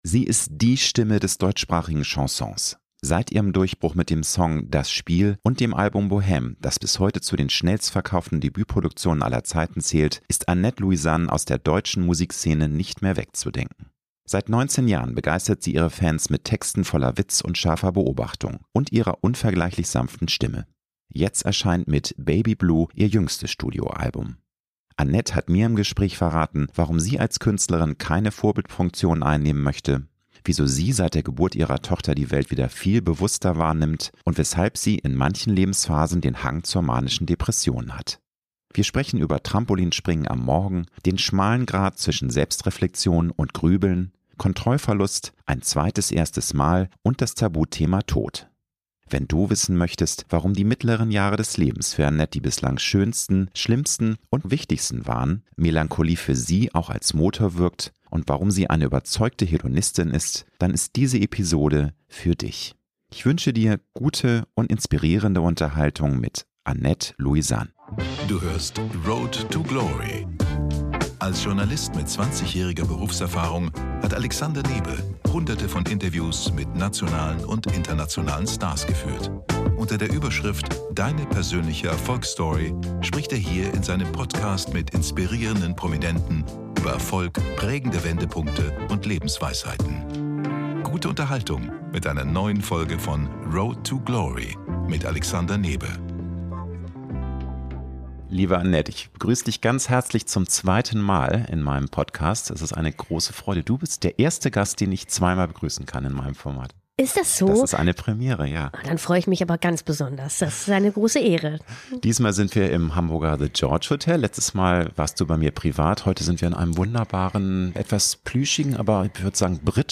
Ich habe Annett Louisan für ROAD TO GLORY bereits zum zweiten Mal getroffen und dabei hat sie mir im Gespräch verraten, warum sie als Künstlerin keine Vorbildfunktion einnehmen möchte, wieso sie seit der Geburt ihrer Tochter die Welt wieder viel bewusster wahrnimmt und weshalb sie in manchen Lebensphasen den Hang zur manischen Depression hat. Wir sprechen über Trampolin springen am Morgen, den schmalen Grad zwischen Selbstreflexion und Grübeln, Kontrollverlust, ein zweites erstes Mal und das Tabuthema Tod.